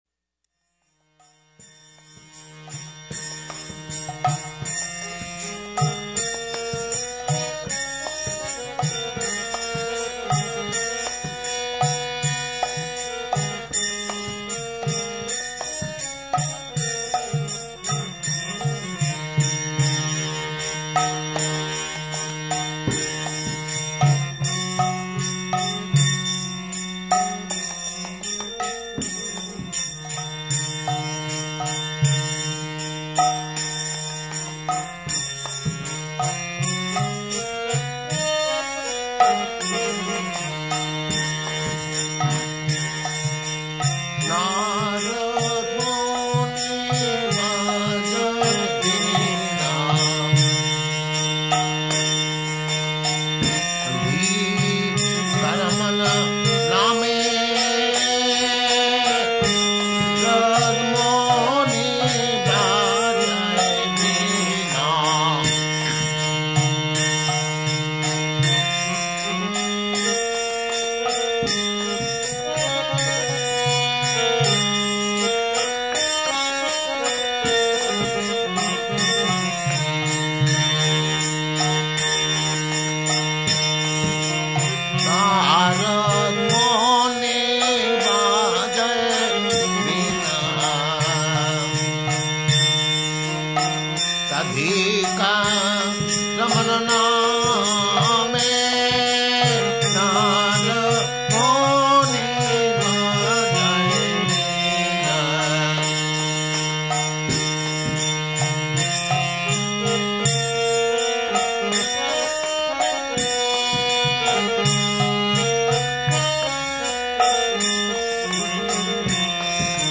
Purport by Srila Prabhupada This is a song sung by Bhaktivinoda Thakura.
CD_03-56__Radhika-ramana-name_Narada_Muni_Bhajaya_Vina__Purport.mp3